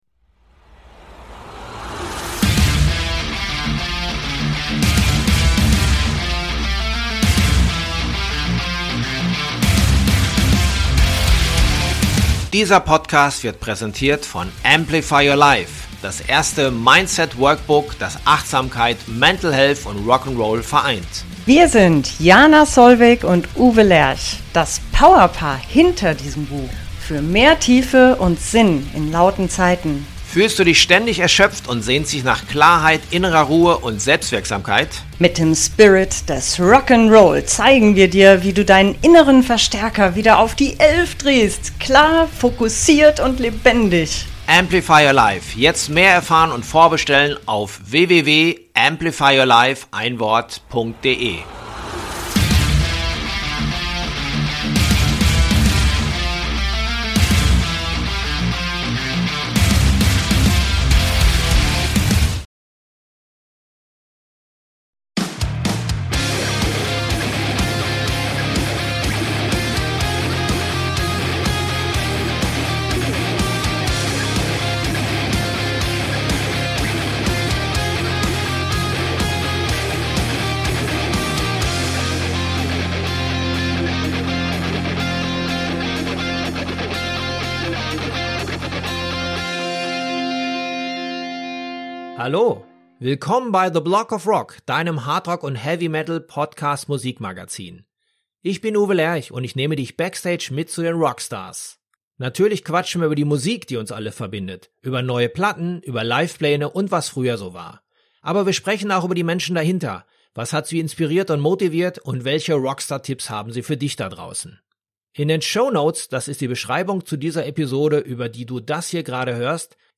in den Studios 301 in Frankfurt
Alle Infos zu diesem außergewöhnlichen Gespräch gibt es hier